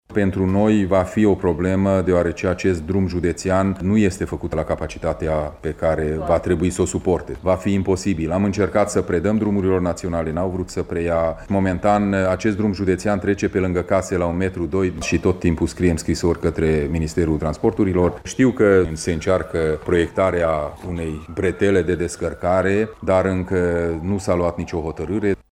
Președintele CJ Mureș, Peter Ferenc spune că problema aglomerației a fost sesizată la Ministerul Transporturilor.